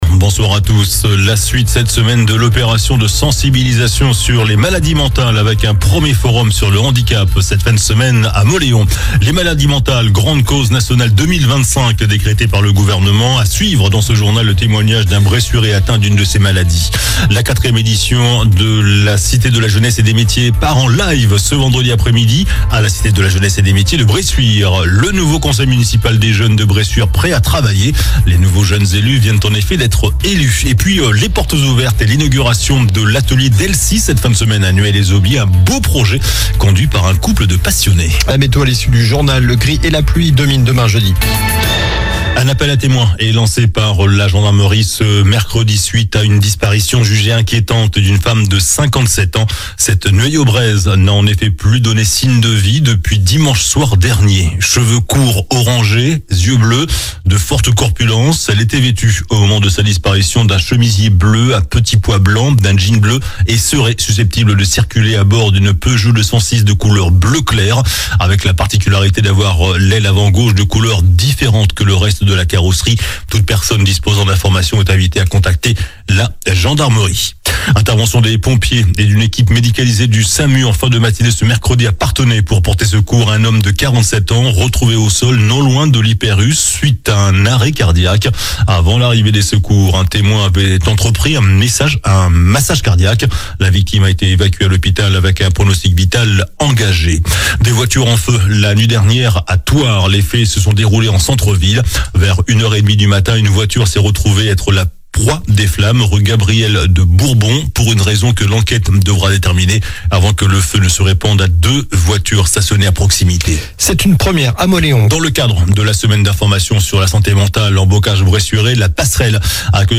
JOURNAL DU MERCREDI 16 OCTOBRE ( SOIR )